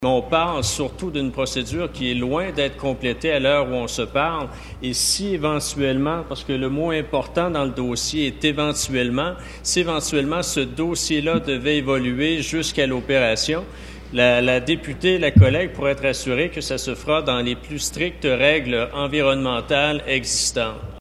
Le ministre de l’Environnement et de la Lutte aux changements climatiques, Benoit Charette, n’a pas fermé la porte à ce qu’un projet d’hydrocarbure se matérialise dans la péninsule :
C’était la première intervention de Méganne Perry Mélançon à la période de questions à l’Assemblée nationale.